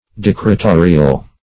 Decretorial \Dec`re*to"ri*al\